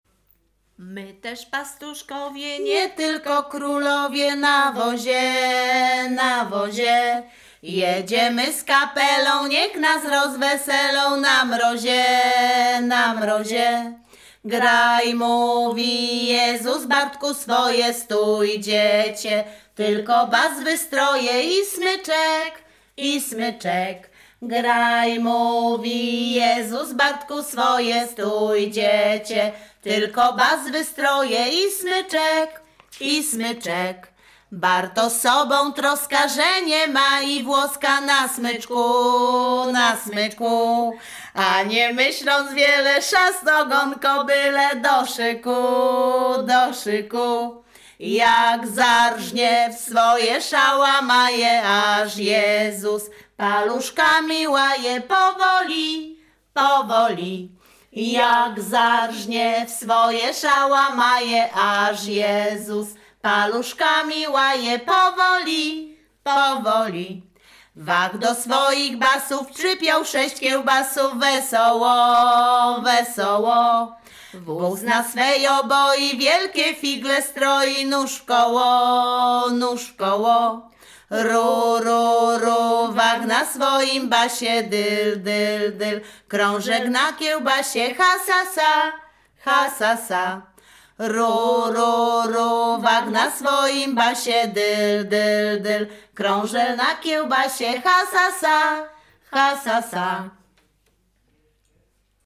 Śpiewaczki z Chojnego
województwo łódzkie, powiat sieradzki, gmina Sieradz, wieś Chojne
Pastorałka
kolędy zimowe